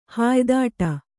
♪ hāydāṭa